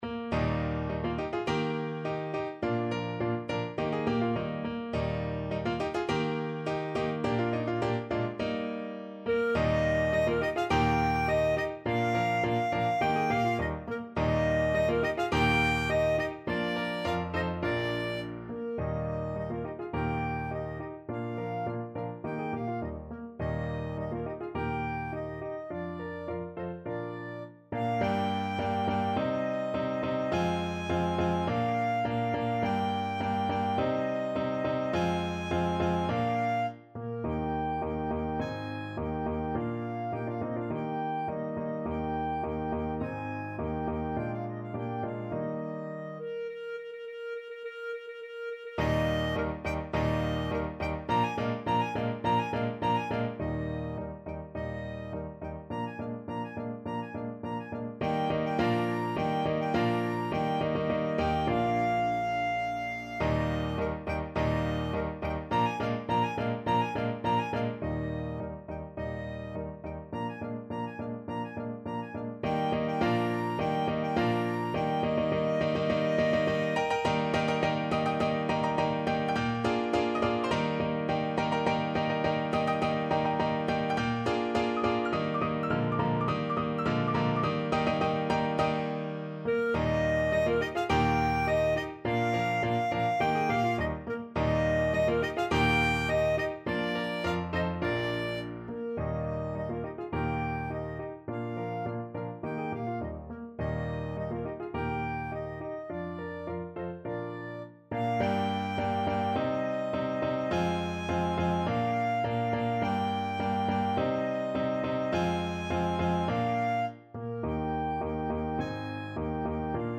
Free Sheet music for Clarinet
Clarinet
Eb major (Sounding Pitch) F major (Clarinet in Bb) (View more Eb major Music for Clarinet )
2/4 (View more 2/4 Music)
~ = 100 Molto vivace =104
Classical (View more Classical Clarinet Music)
weber_hunters_chorus_CL.mp3